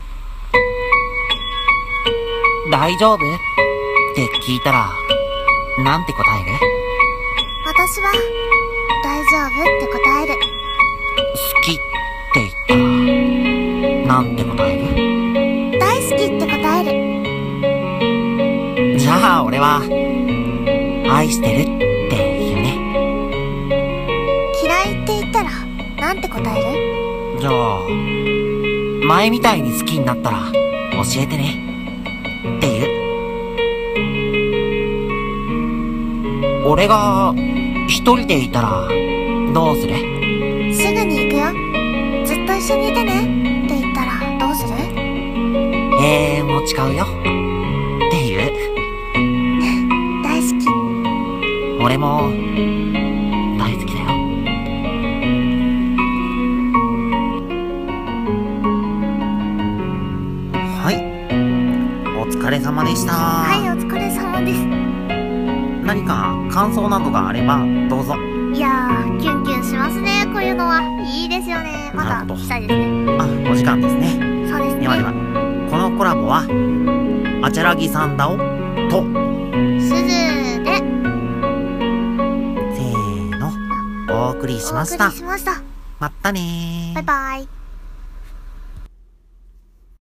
【コラボ用】声劇 男女掛け合いアフレコ 女性用